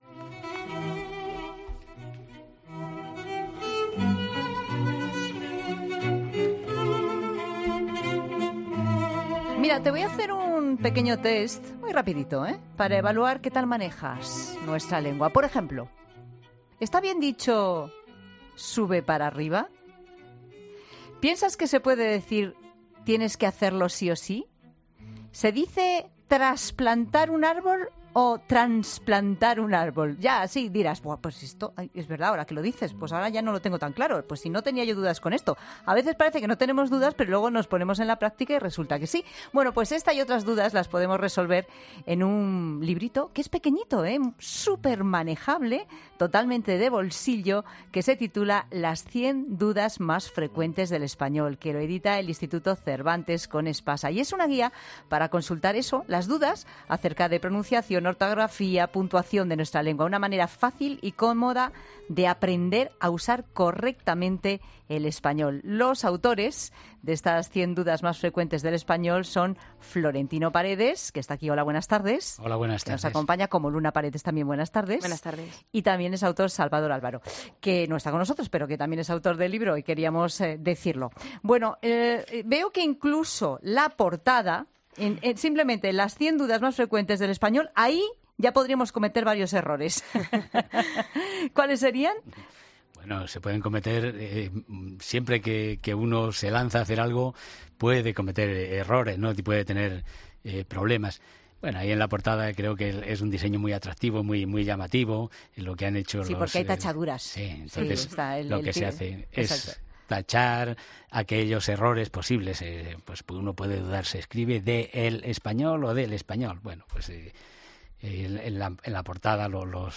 Le planteamos algunas dudas desde el estudio de COPE como: ¿debería usarse 'español' o 'castellano'? Sobre esta cuestión tienen claro que ambas acepciones están incluidas, pueden utilizarse y “no hay por qué hace un debate de ello”.